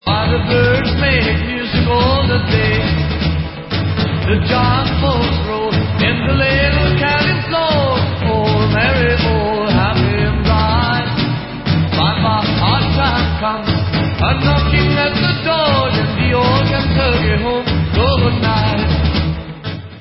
sledovat novinky v oddělení Pop/Instrumental